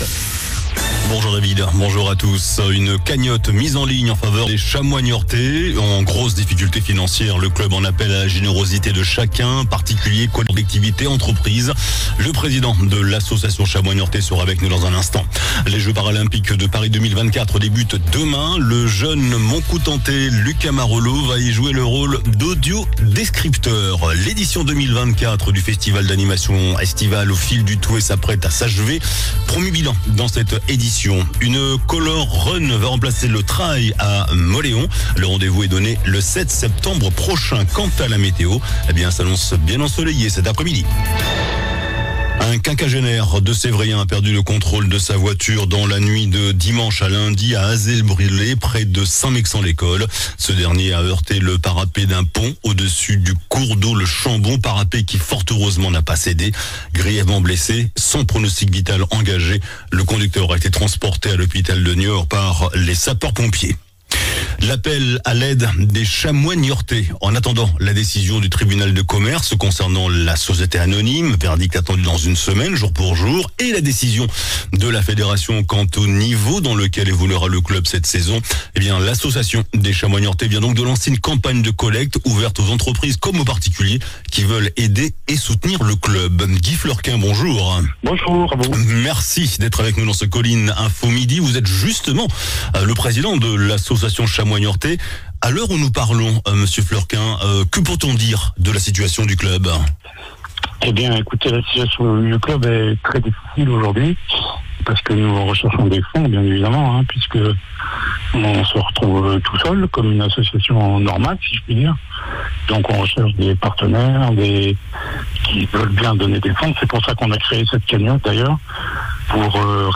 JOURNAL DU MARDI 27 AOÛT ( MIDI )